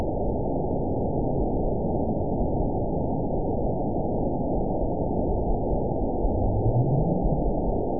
event 921467 date 10/16/24 time 01:21:49 GMT (8 months ago) score 9.63 location TSS-AB06 detected by nrw target species NRW annotations +NRW Spectrogram: Frequency (kHz) vs. Time (s) audio not available .wav